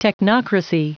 Prononciation du mot technocracy en anglais (fichier audio)
Prononciation du mot : technocracy